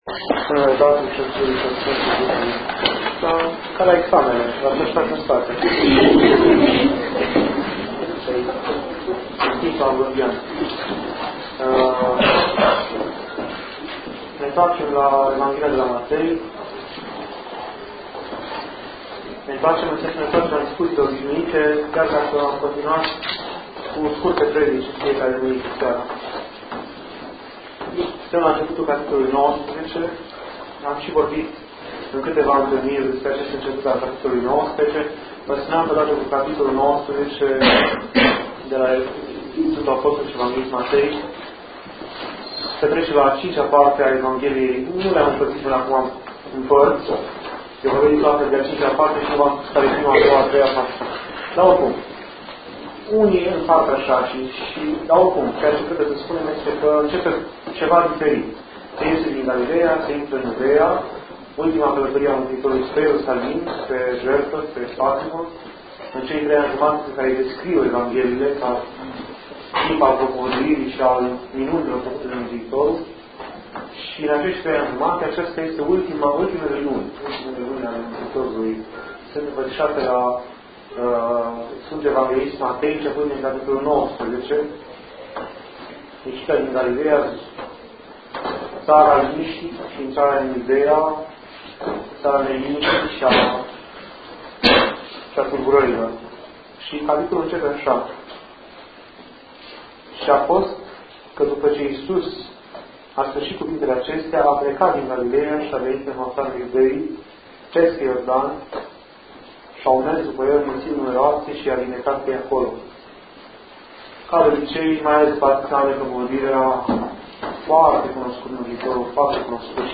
Discutii Faptele Apostolilor. Despre rugaciune
Aici puteți asculta și descărca înregistrări doar de la Bisericuța din Hașdeu